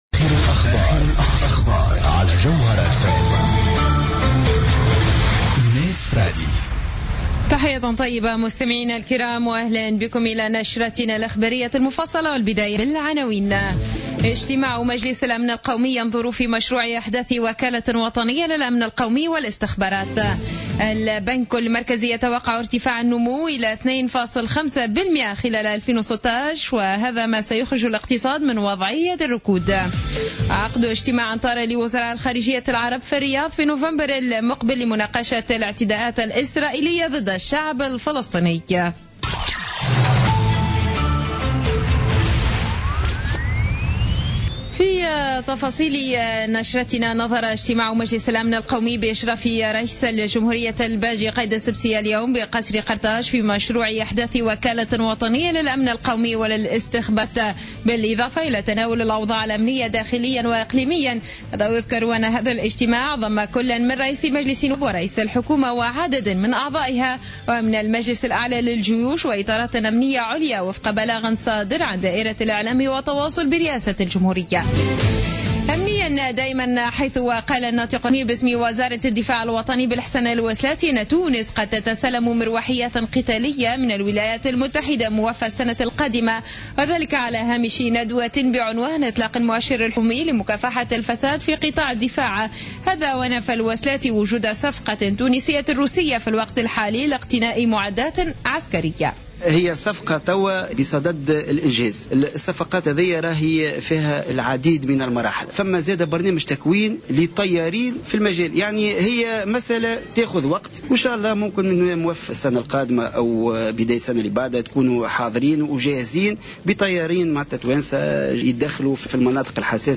نشرة أخبار السابعة مساء ليوم الخميس 29 أكتوبر 2015